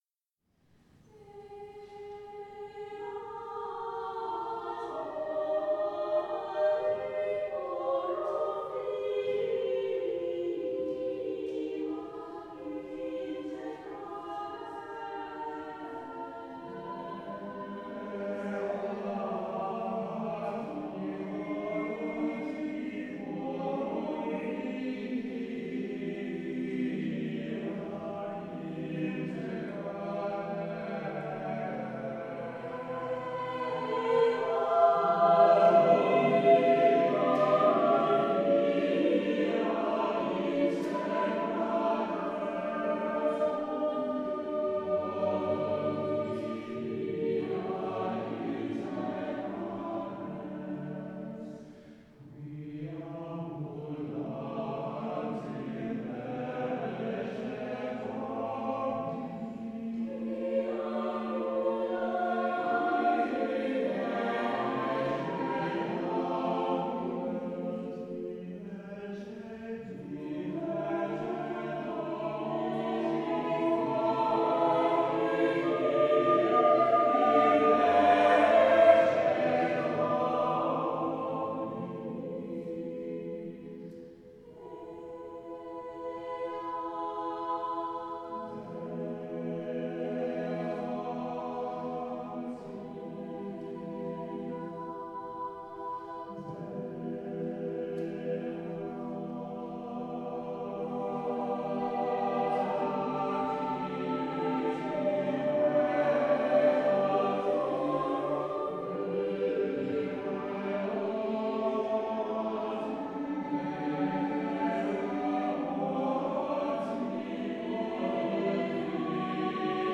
English choral music as it might have been sung
in the Priory Church through the centuries